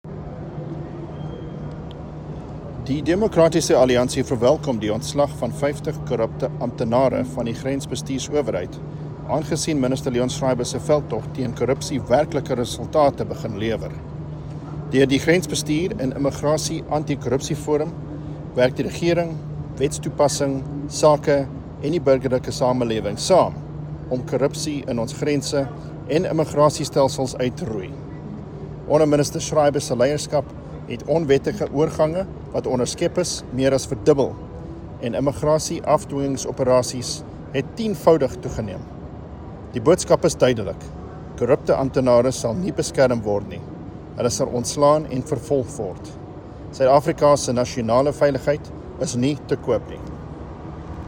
Issued by Adrian Roos MP – DA Spokesperson on Home Affairs
Afrikaans and